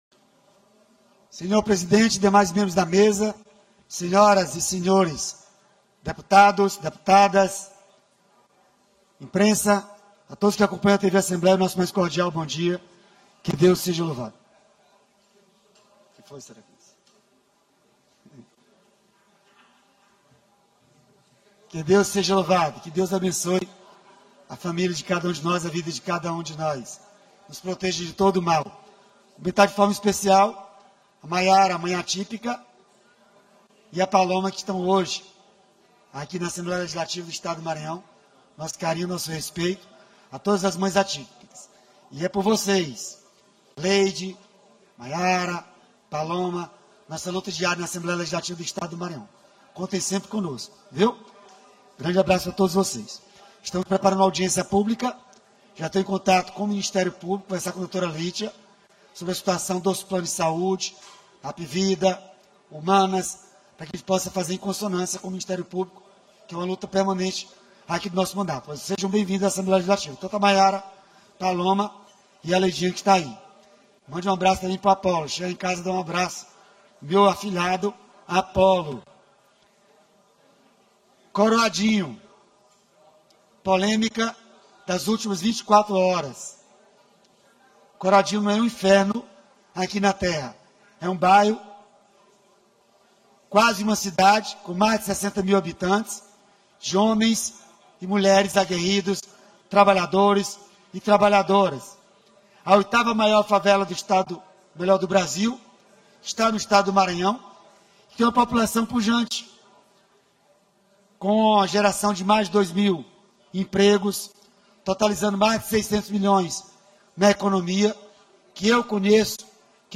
Início -> Discursos